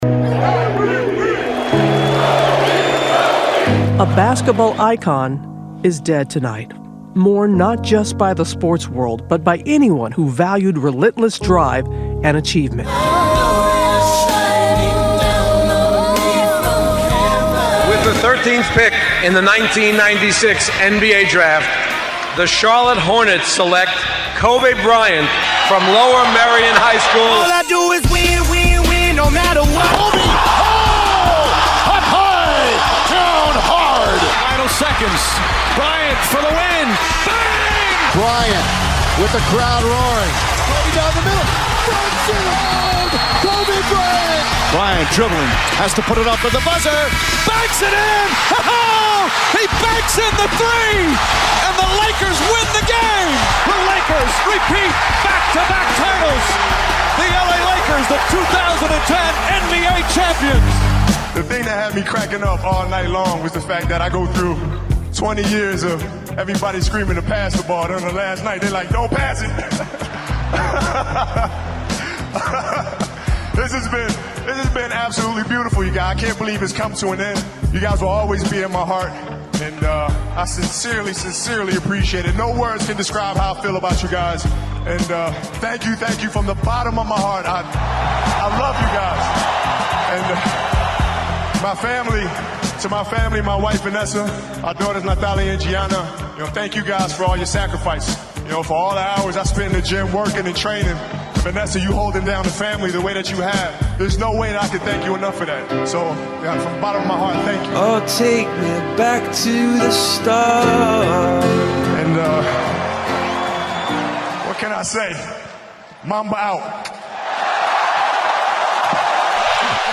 Kobe-Final-final-montage-SHORT-PLAY-THIS-ONE-.mp3